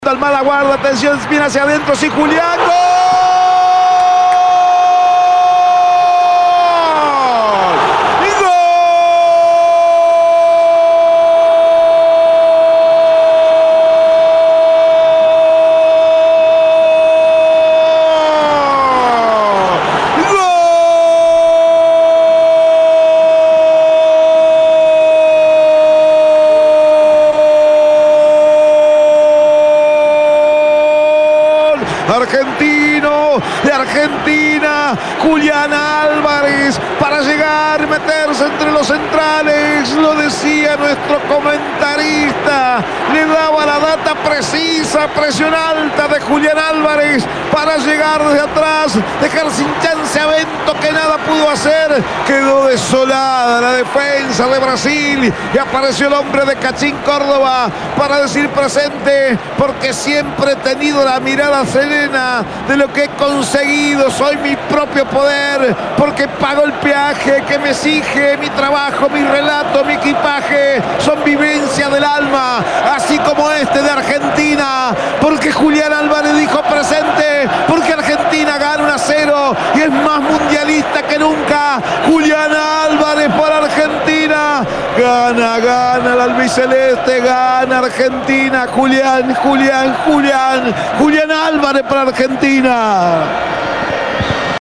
EN EL RELATO